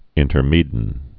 (ĭntər-mēdn)